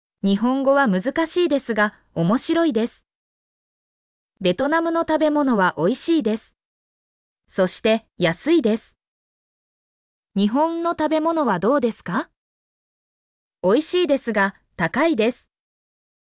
mp3-output-ttsfreedotcom-55_D41OUYVA.mp3